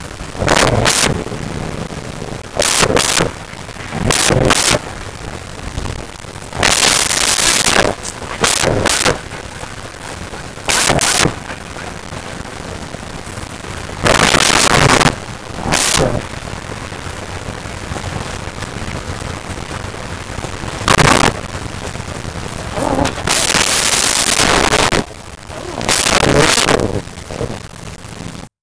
Dog Small Barking
Dog Small Barking.wav